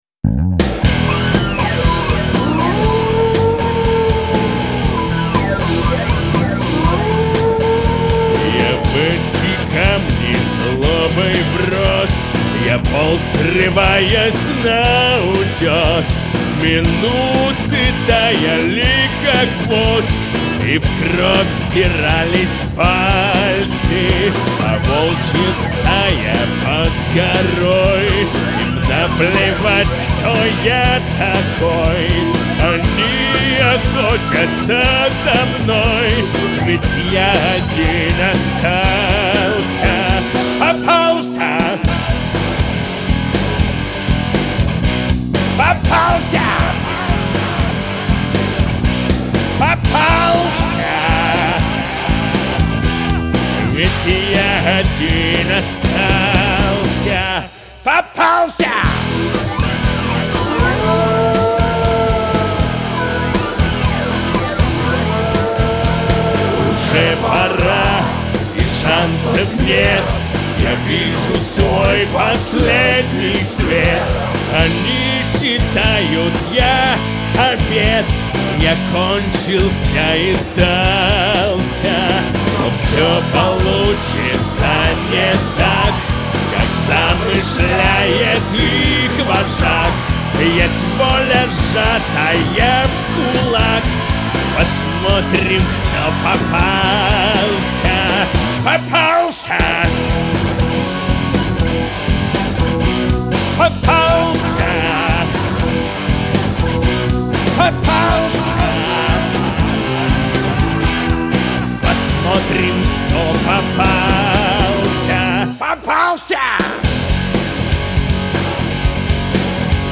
гусли, бэк-вокал.